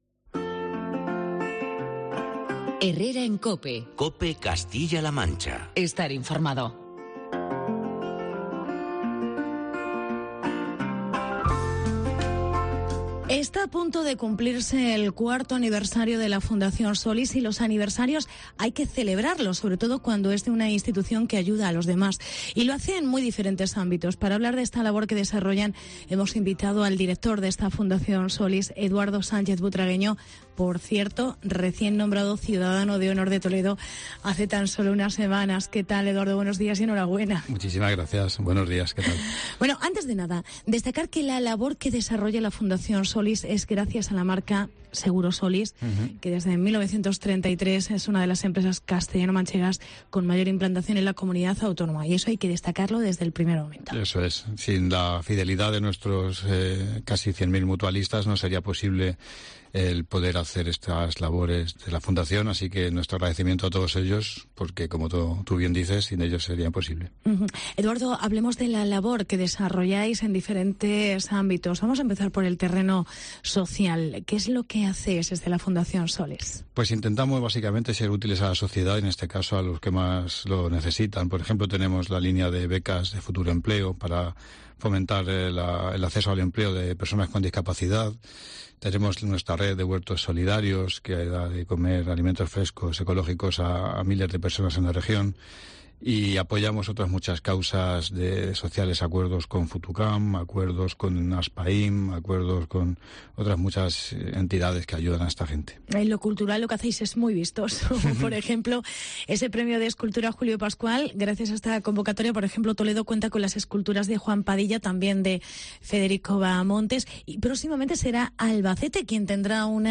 AUDIO: 4º Aniversario de la Fundación Soliss. Entrevista